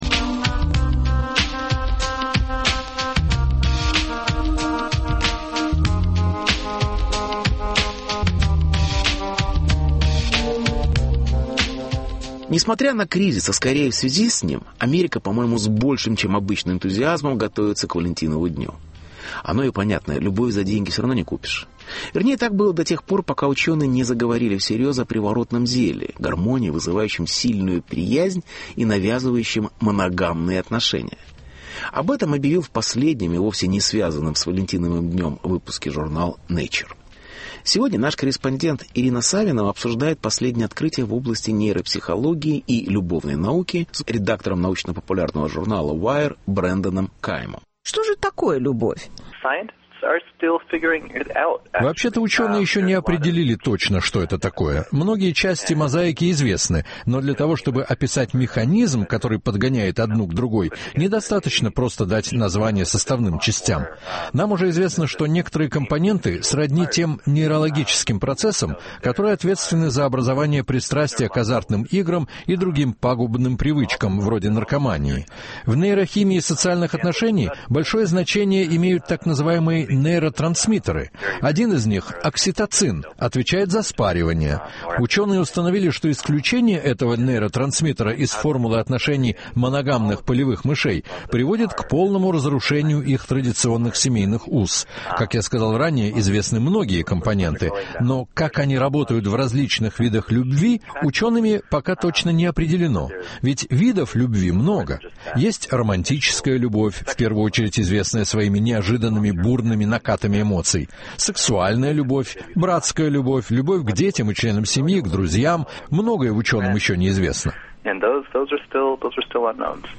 Интервью: приворотное зелье и другие новости любовной науки.